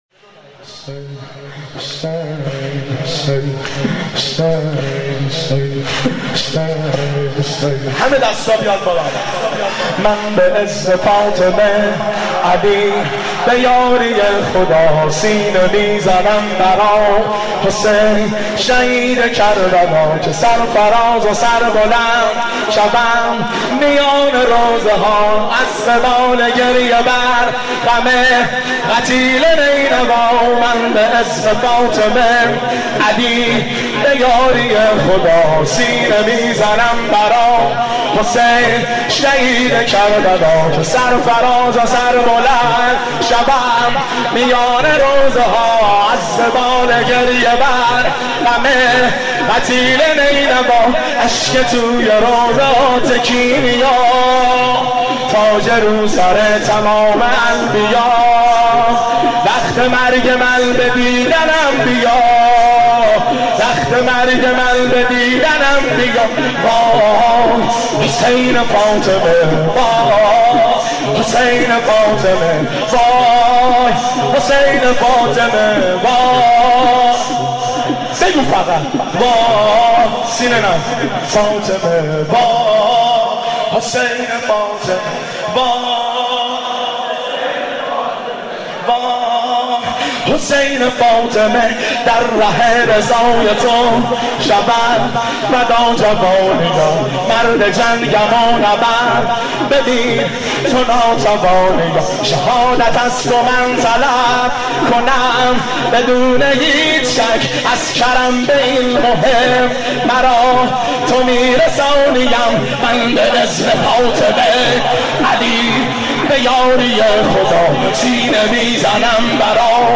قالب : شور